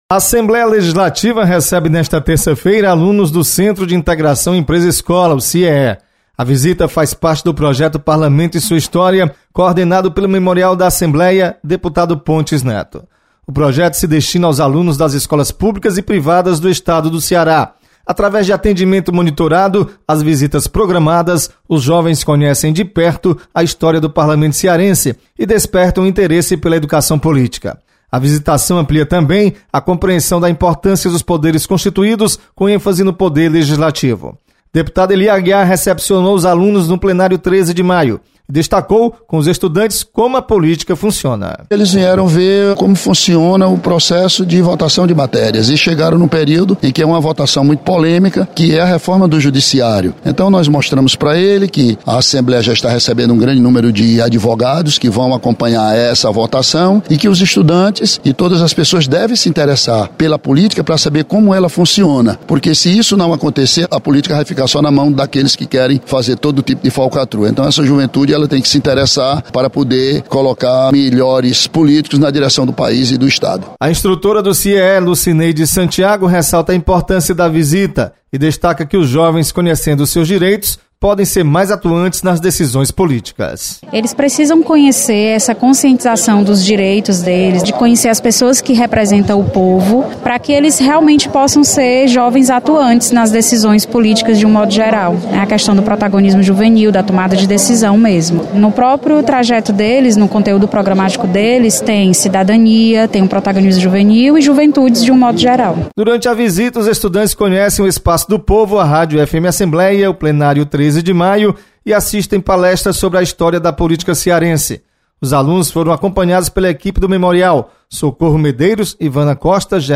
Alunos do CIEE visitam a Assembleia Legislativa. Repórter